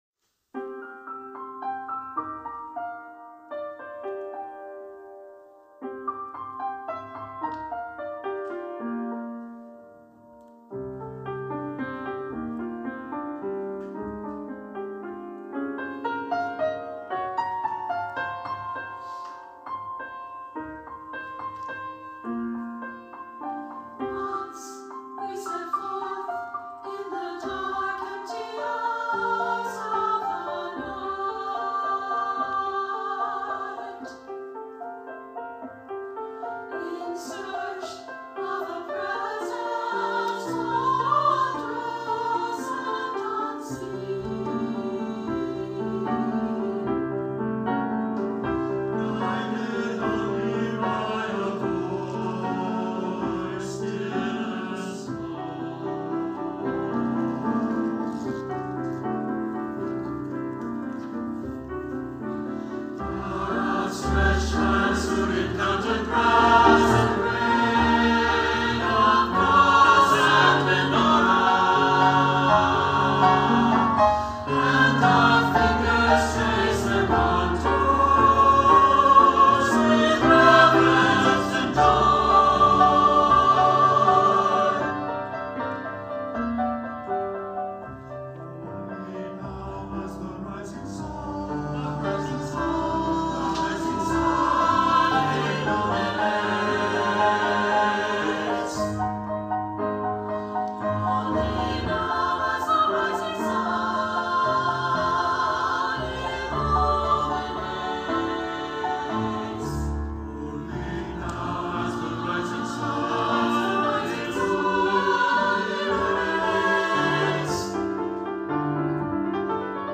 SATB, flute, horn, violin, cello, piano OR SATB, piano
With shifting harmonies and luminous voicings